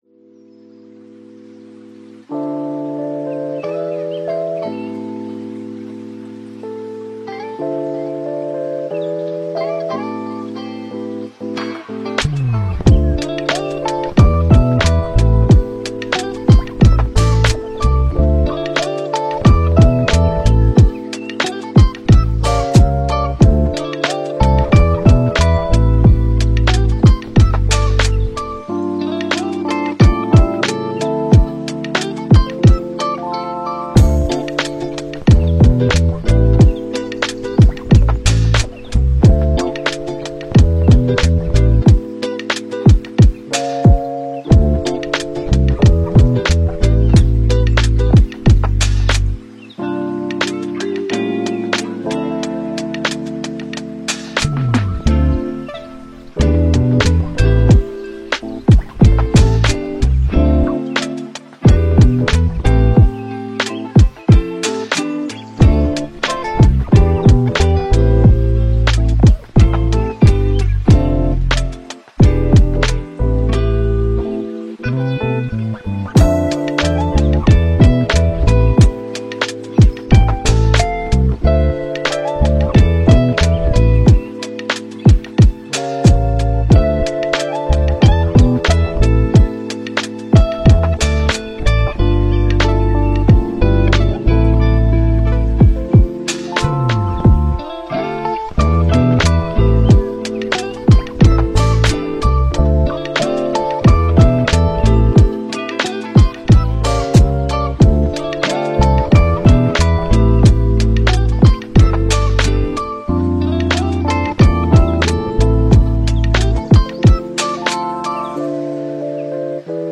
Мы подобрали для вас лучшие lo-fi песни без слов.
Весёлая мелодия 🎶